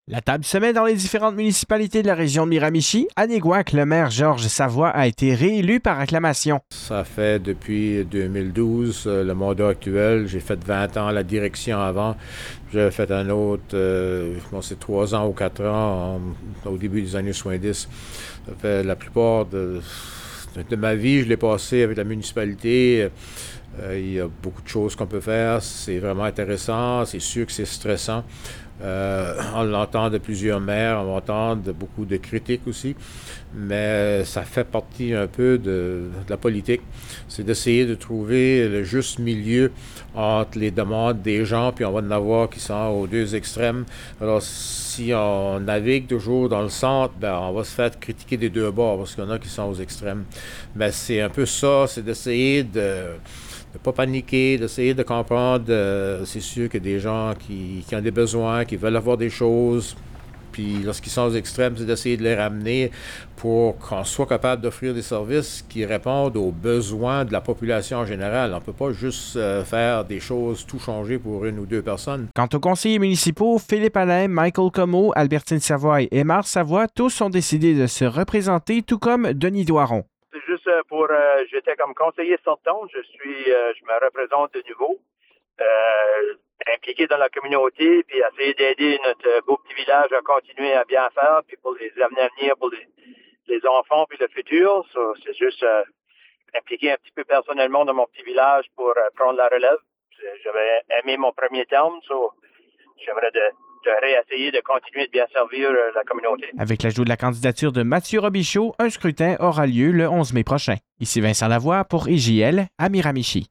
Un reportage